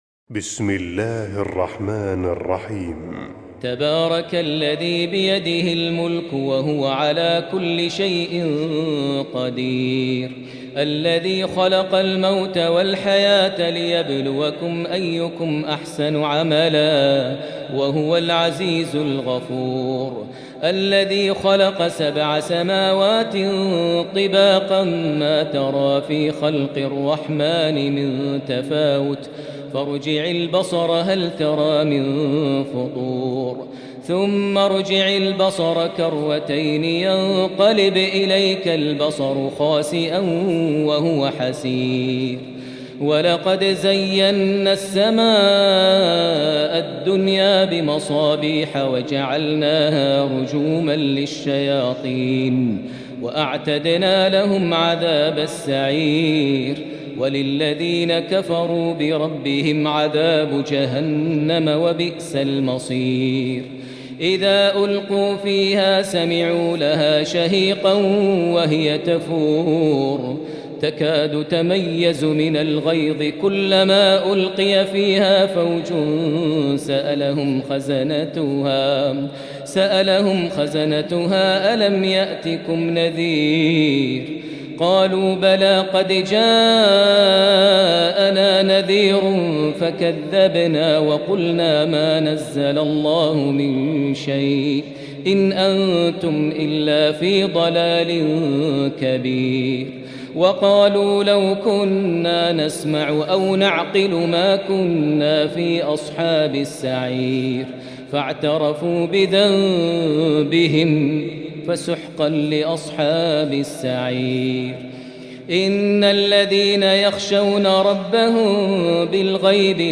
Surah Al Mulk Maher Al Muaqili-سورة الملك بصوت الشيخ الدكتور ماهر المعيقلي > تلاوات عطرة للشيخ ماهر المعيقلي > مزامير الفرقان > المزيد - تلاوات الحرمين